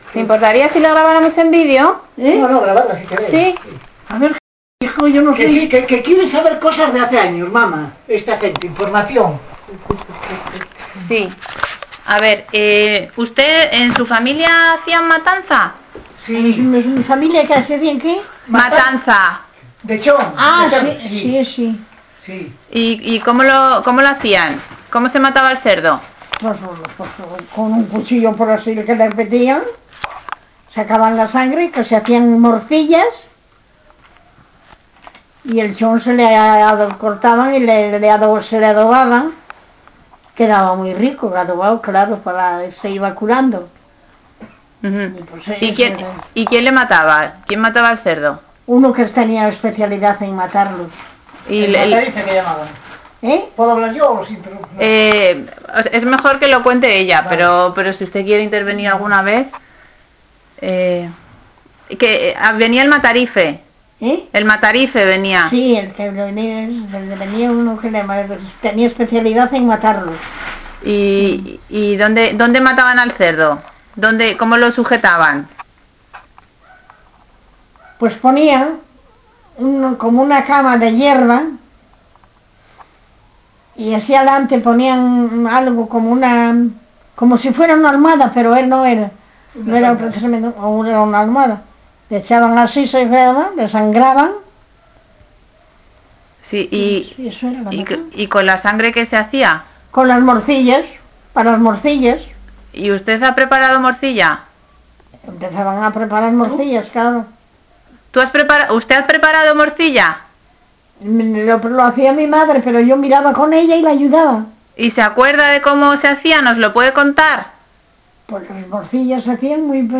Enclave Obreg�n (Villaescusa)
Encuesta
I1:�mujer-